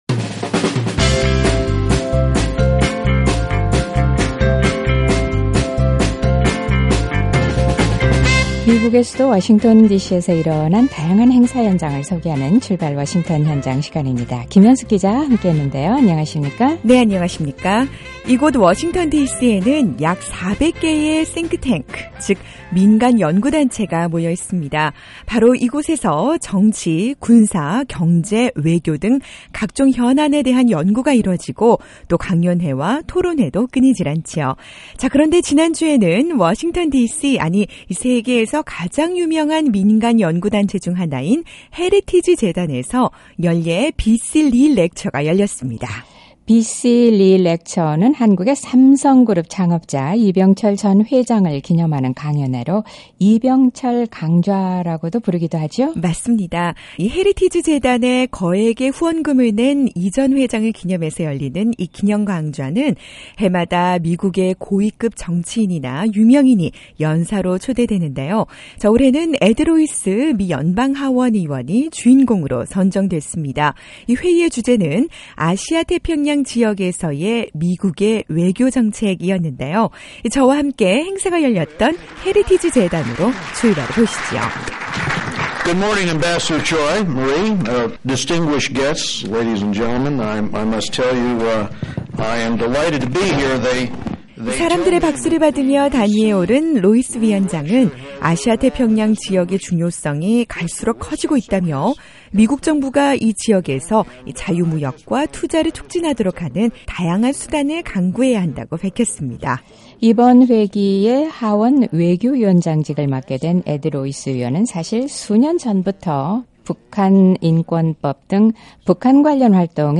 워싱턴디씨에 위치한 민간연구단체 헤리티지 재단에서는 매년 ‘이병철 강좌(B.C.Lee Lecture)’를 개최합니다. 이 행사는 주로 미국의 고위급 정치인이 연사로 초청되는데요, 올해는 에드 로이스 미 연방하원 외교위원장이 초청돼 ‘아시아-태평양지역에서의 미국의 외교정책’이라는 주제로 강연을 했습니다. 이번 강연에선 특히 북한과 관련한 내용이 많아 관심을 끌었는데요 바로 그 현장으로 출발해보시죠!